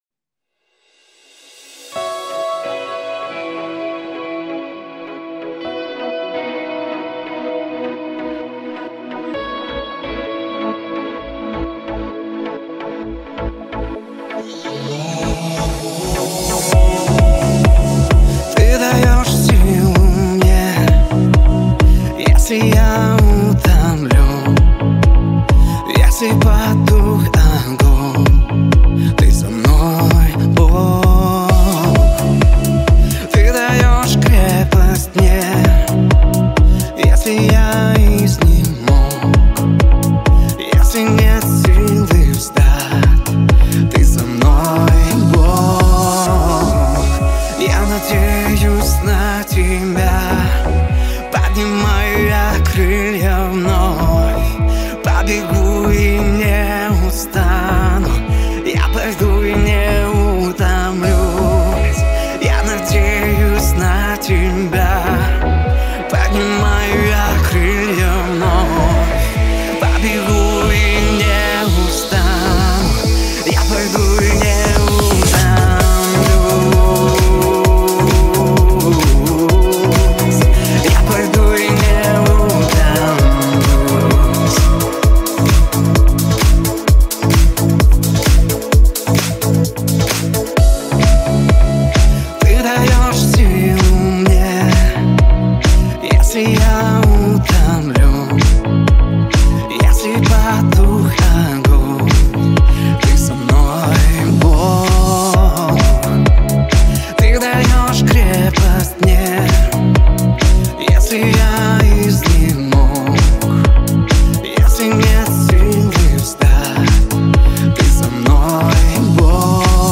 BPM: 130